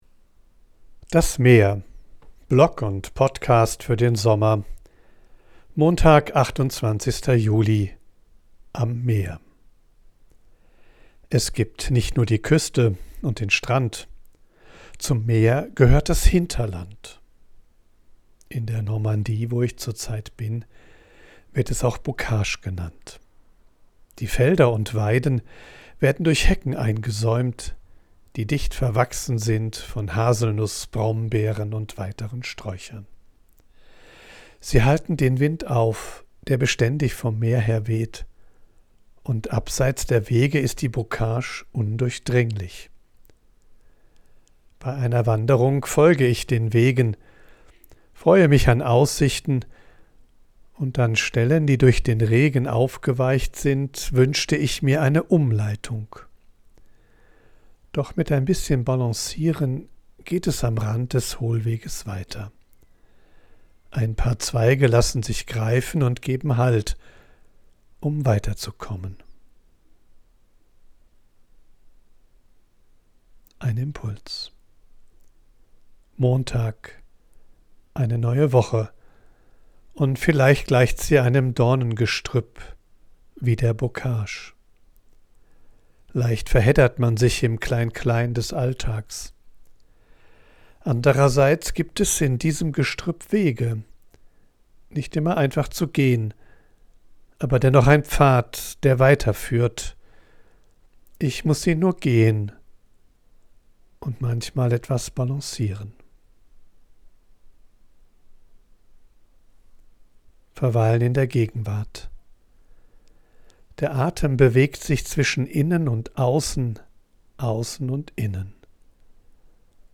Ich bin am Meer und sammle Eindrücke und Ideen.
von unterwegs aufnehme, ist die Audioqualität begrenzt.
mischt sie mitunter eine echte Möwe und Meeresrauschen in die